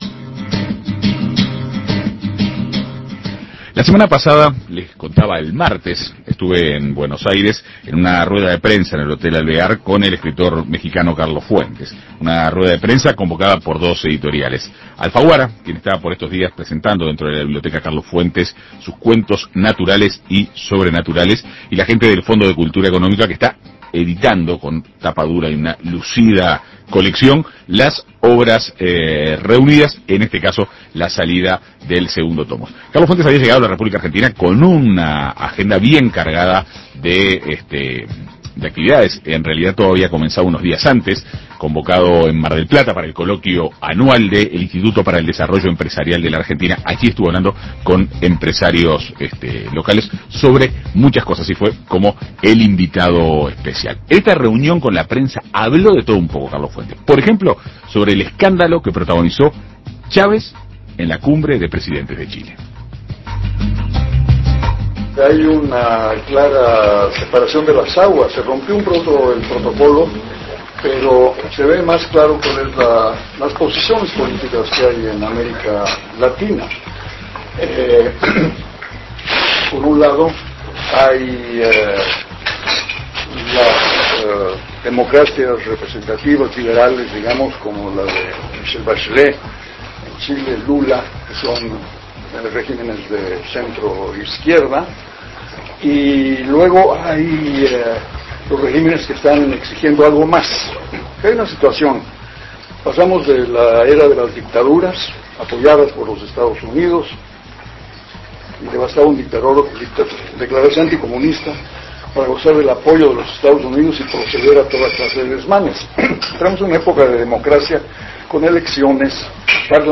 En una rueda, convocada por la editorial Alfaguara y por el Fondo de Cultura Económica, en Buenos Aires